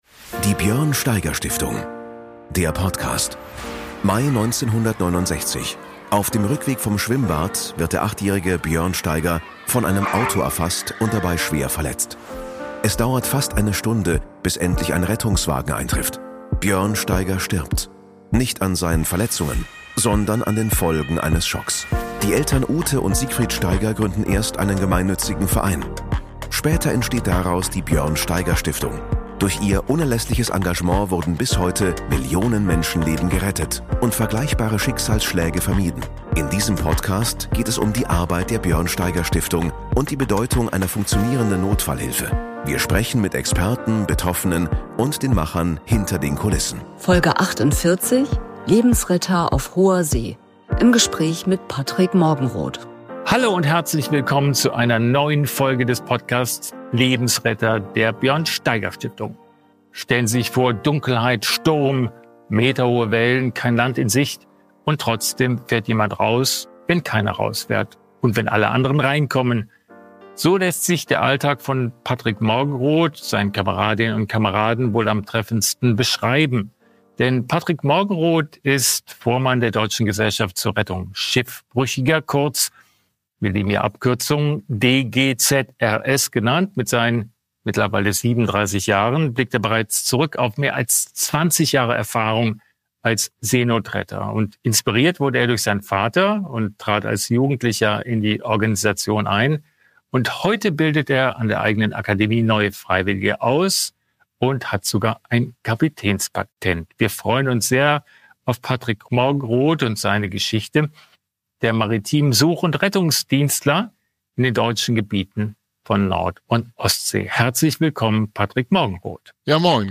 Ein Gespräch über Mut, Verantwortung, Teamgeist – und über das unerschütterliche Vertrauen in Technik, Crew und Menschlichkeit auf hoher See.